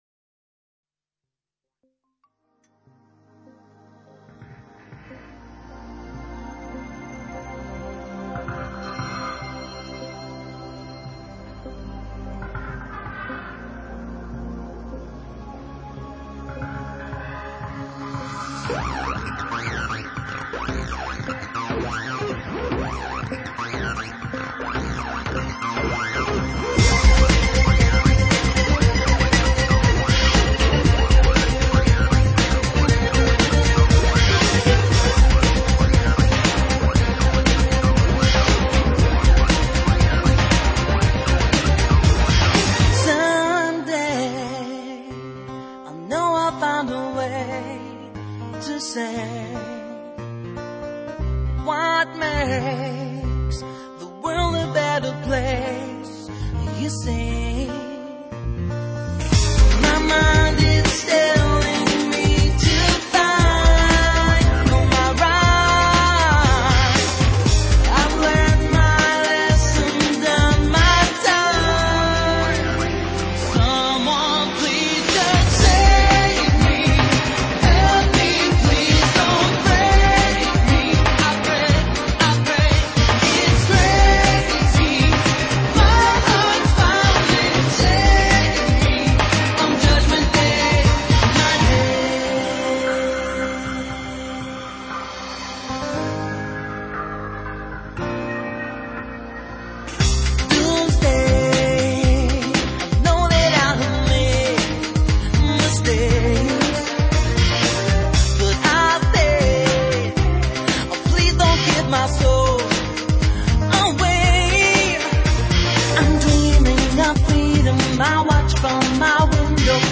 英文说唱